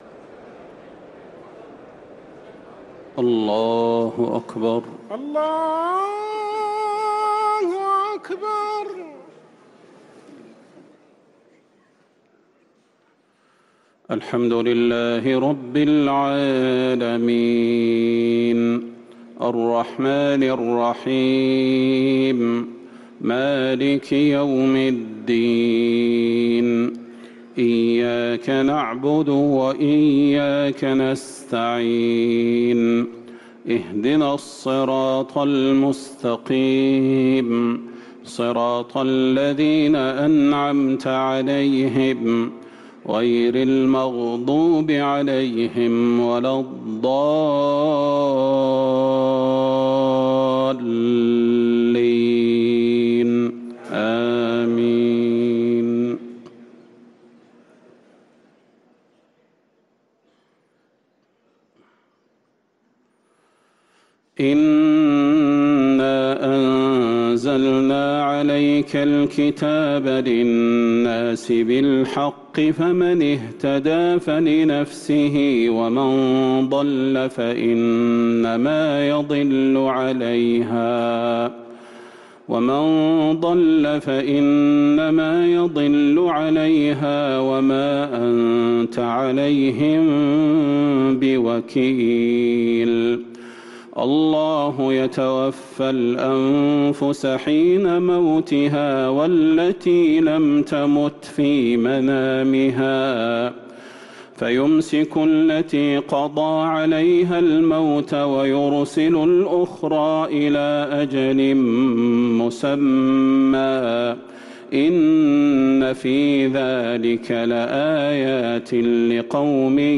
صلاة التراويح ليلة 25 رمضان 1444 للقارئ خالد المهنا - التسليمتان الأخيرتان صلاة التراويح